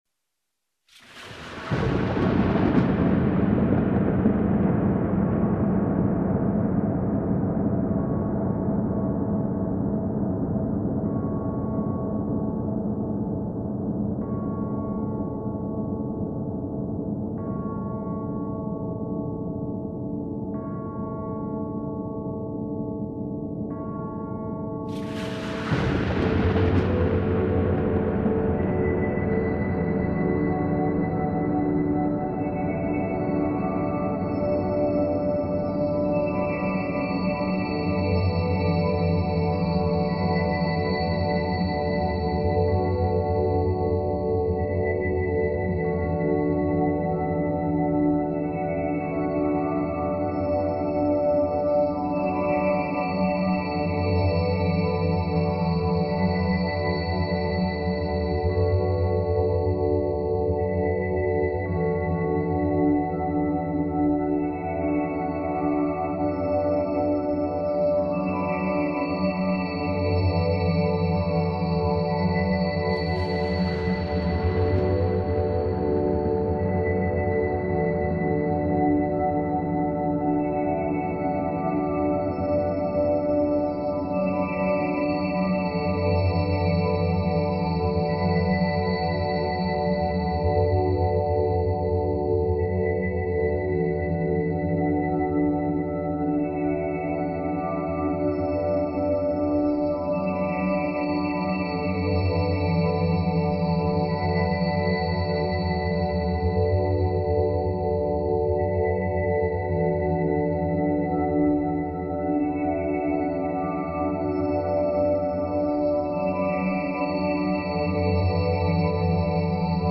transcendent electronic music